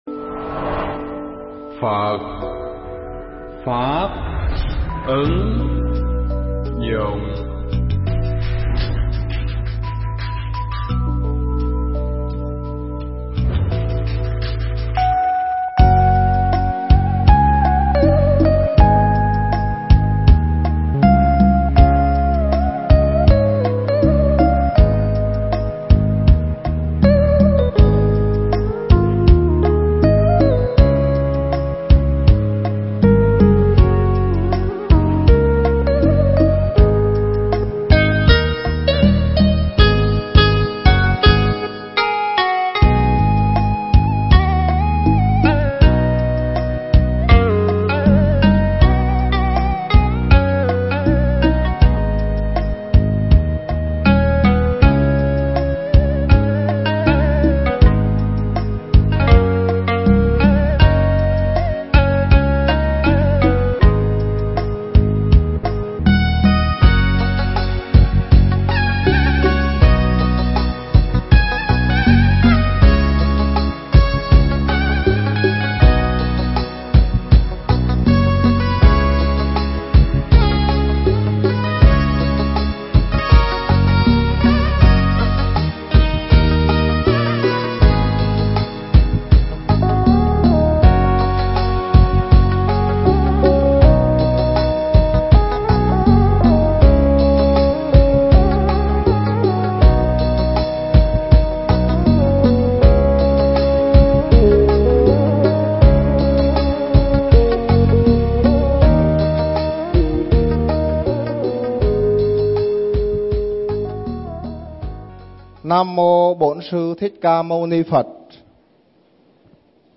Mp3 Thuyết Pháp Phương Trời Thong Dong
giảng tại chùa Giác Ngộ nhân khóa tu Ngày An Lạc lần 2